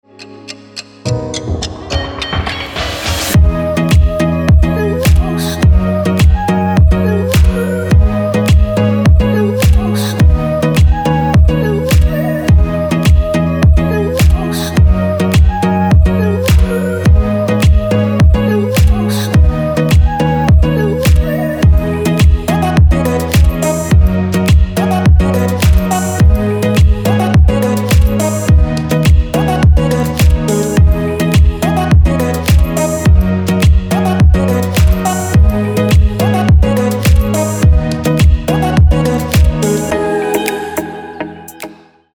• Качество: 320, Stereo
красивые
dance
Electronic
house